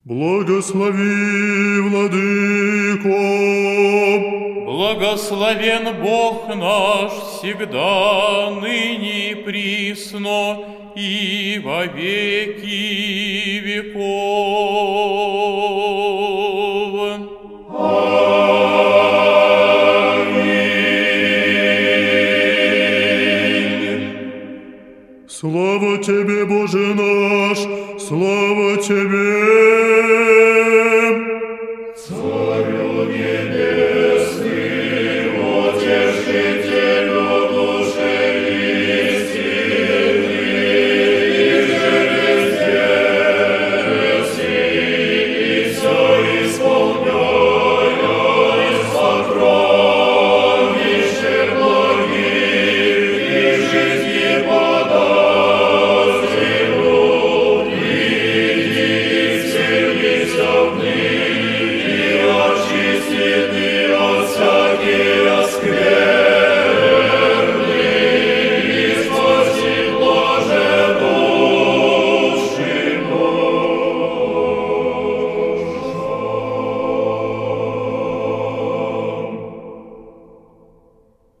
Аудиокнига Акафист Пантелеимону великомученику и целителю | Библиотека аудиокниг